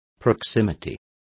Also find out how proximidad is pronounced correctly.